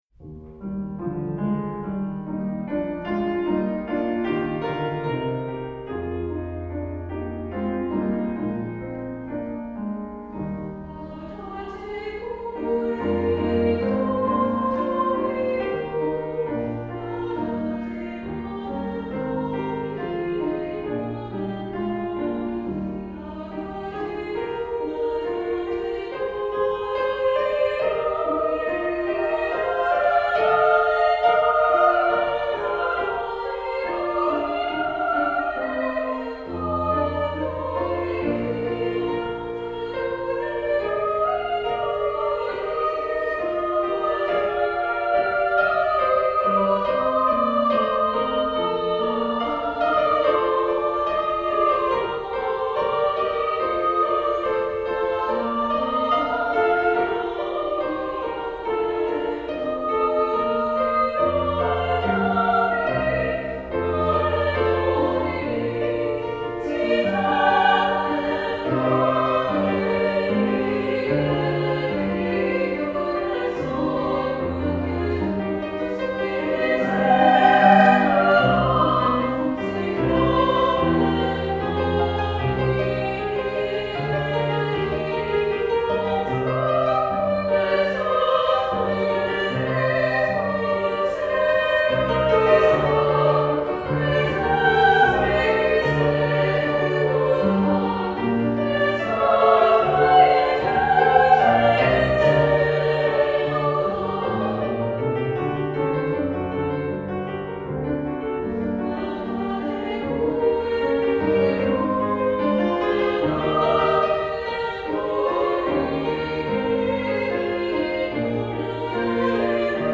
Accompaniment:      Keyboard
Music Category:      Choral